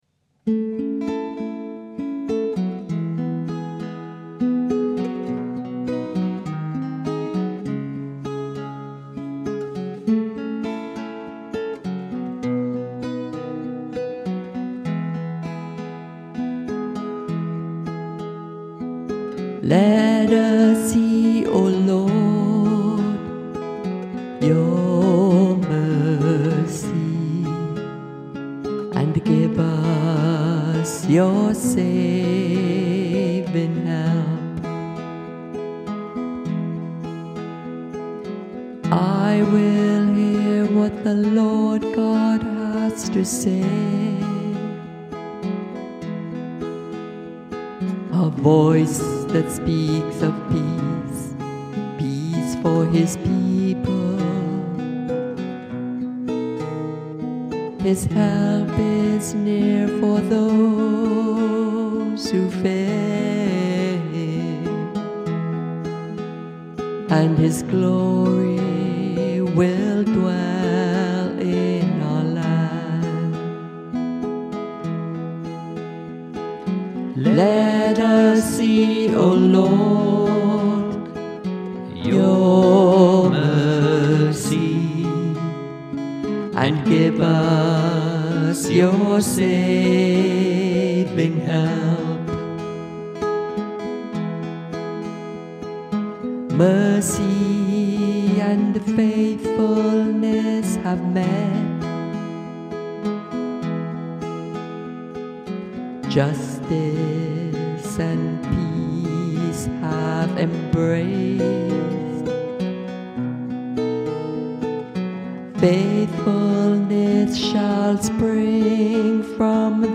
The music for the psalm is an original OLOR composition.
Music by the Choir of Our Lady of the Rosary RC Church, Verdun, St. John, Barbados.